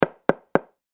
ドアノック1.mp3